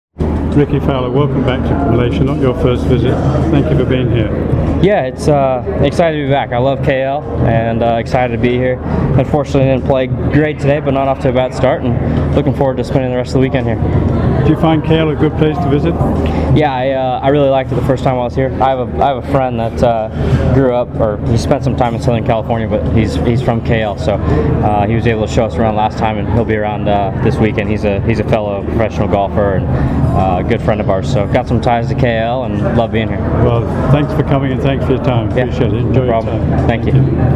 MGTA interviews Rickie Fowler